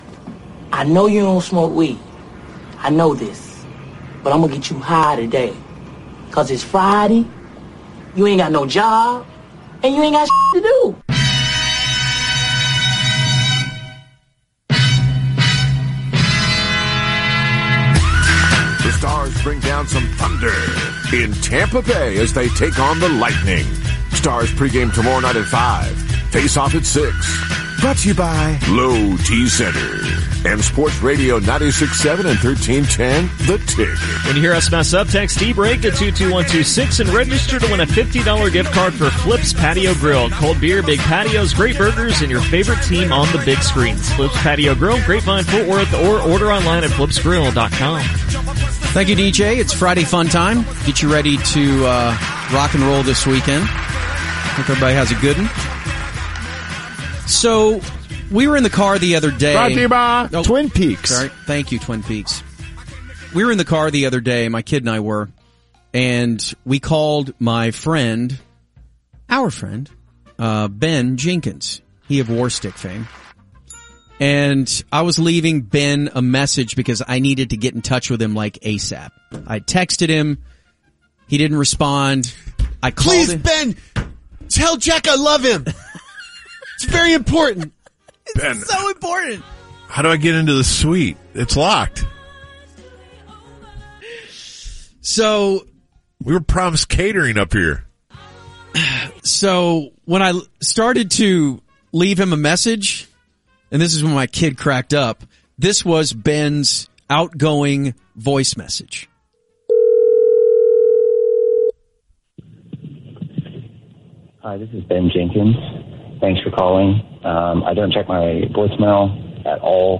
Voicemail fun.